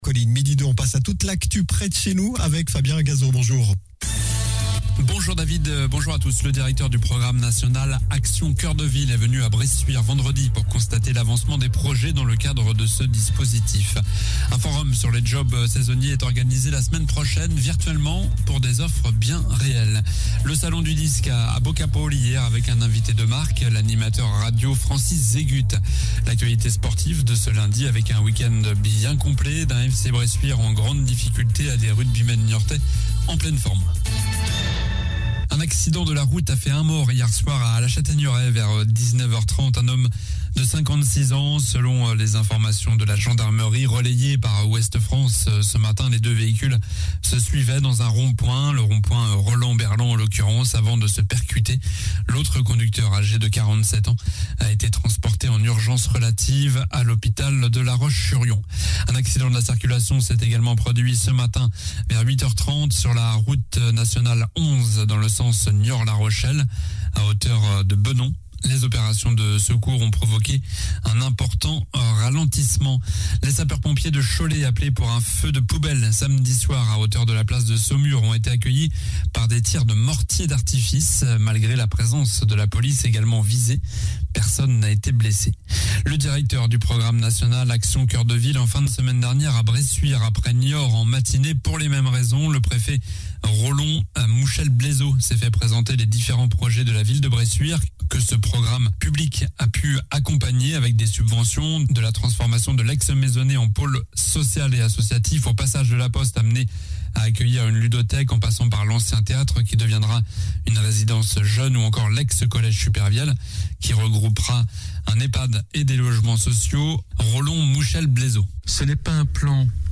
Journal du lundi 14 février (midi)